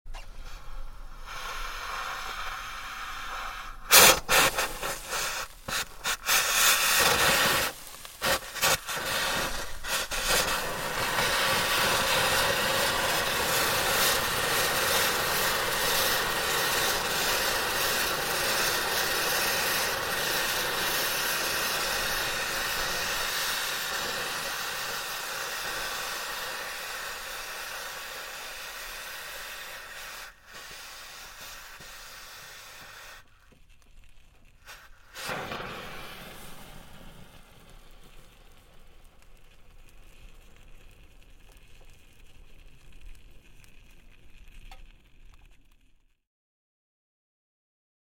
Звуки поломки автомобиля
Кипящий звук радиатора и пар из него